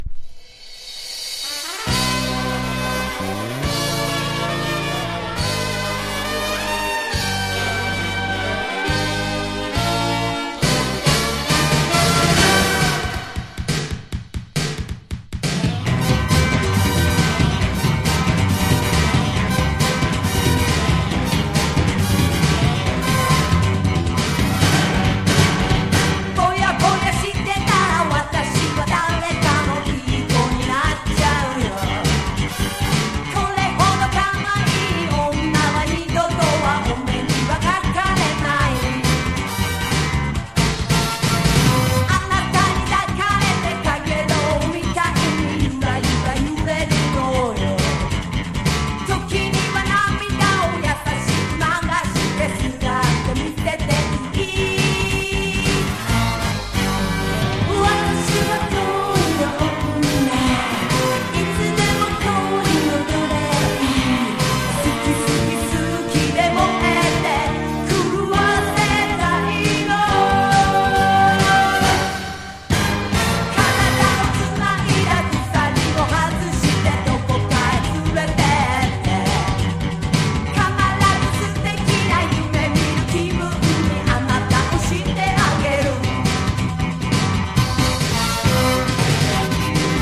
60-80’S ROCK
FOLK# CLUB# 和モノ / ポピュラー